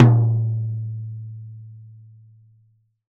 Acoustic High Tom 01.wav